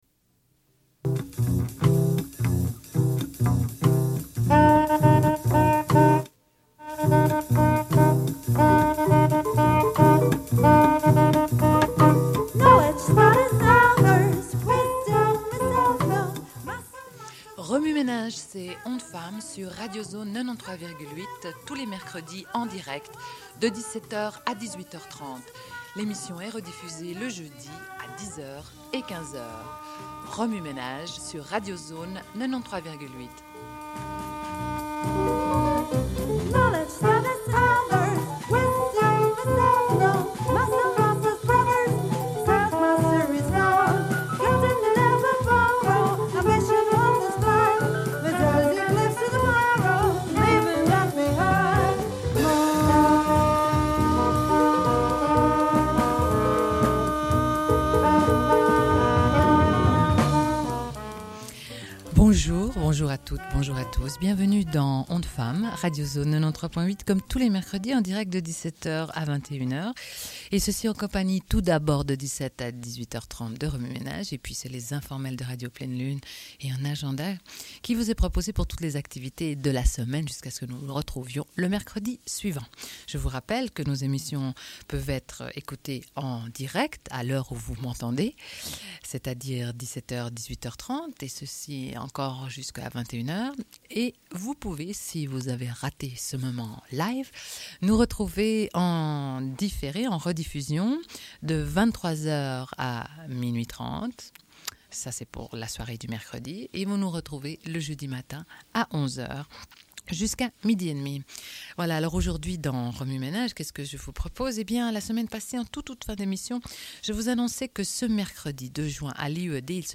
Une cassette audio, face A31:11